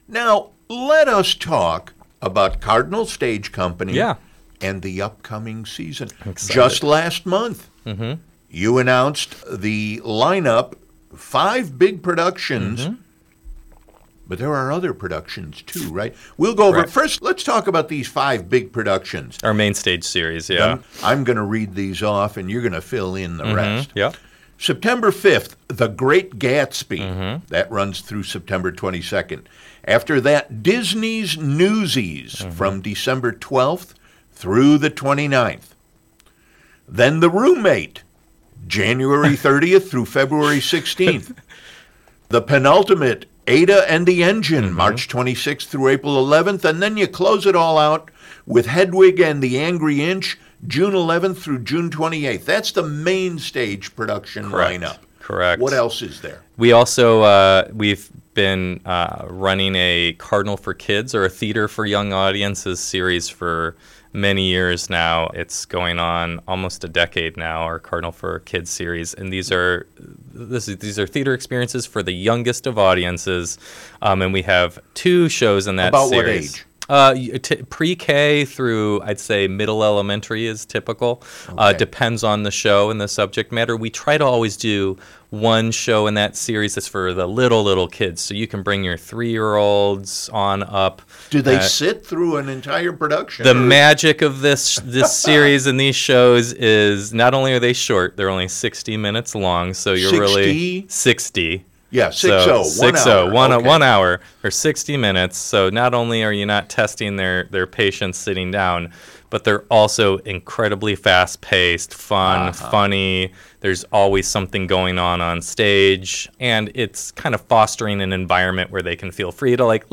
BTE is an approximately eight-minute feature every Monday on the ‘FHB’s daily newscast at 5pm offering added conversation from the previous week’s Big Talk.